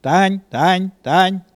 Cri pour appeler les vaches ( prononcer le cri )